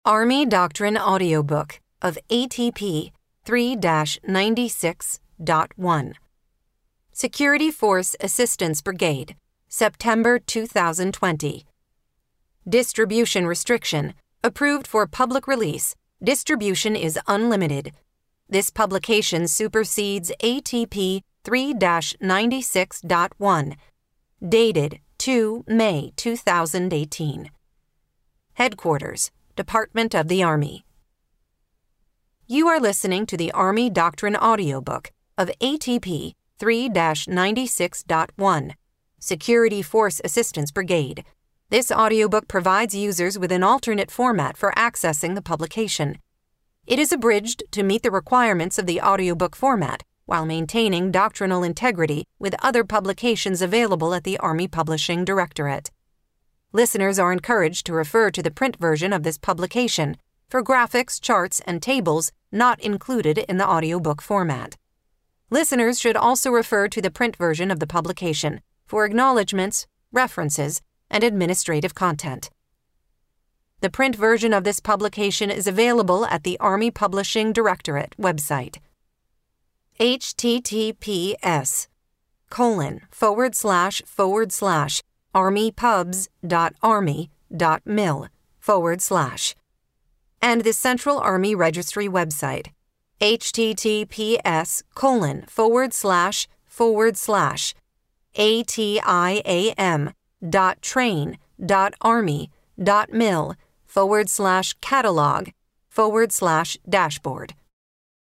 Army Doctrine Audiobook Download Page
The Army Doctrine Audiobook provides users with an alternate format for accessing ATP 3-96.1. It has been abridged to meet the requirements of the audiobook format.